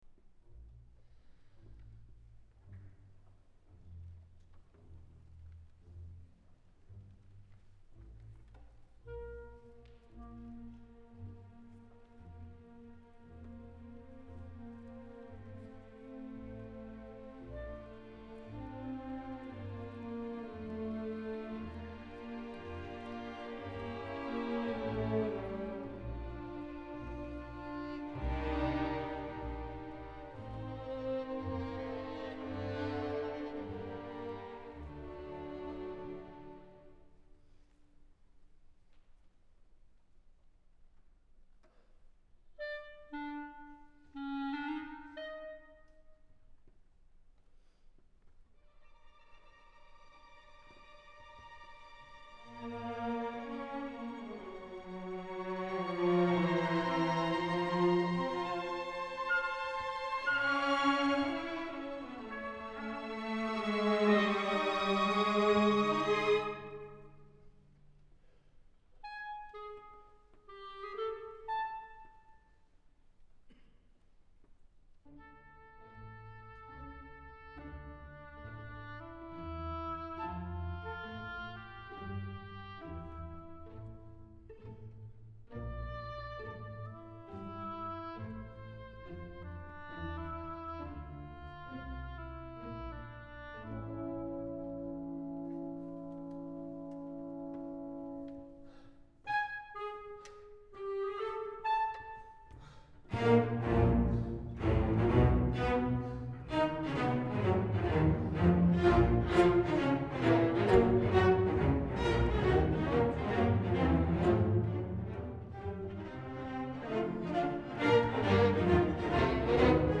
Concert Choir
for the refulgent closing reprise of the chorale theme